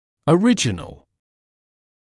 [ə’rɪʤənl][э’риджэнл]первоначальный, исходный; оригинальный